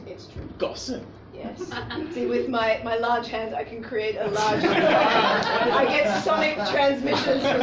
Tags: rap